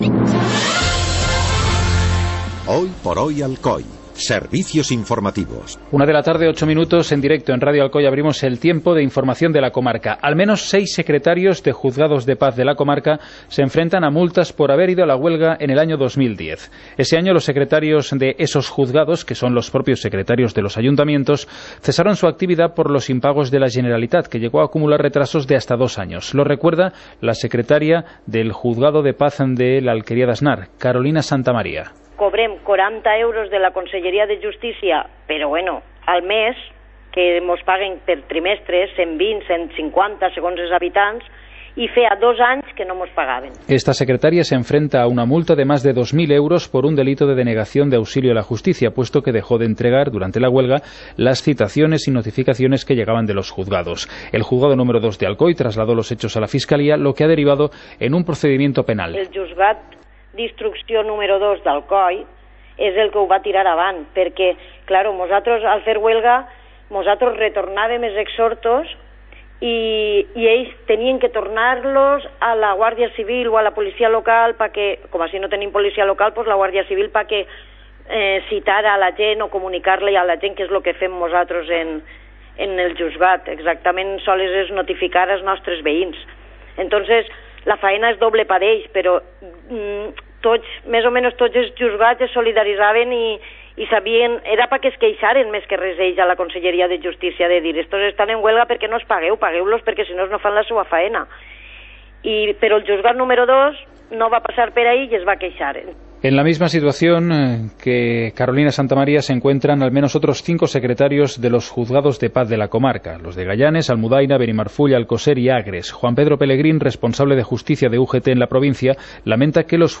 Informativo comarcal - martes, 11 de octubre de 2016